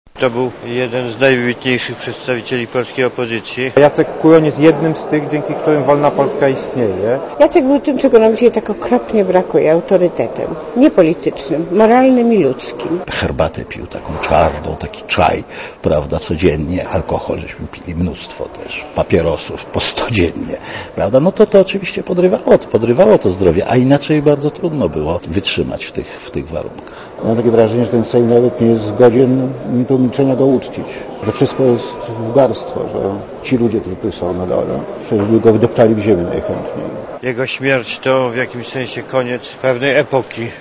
Parlamentarzyści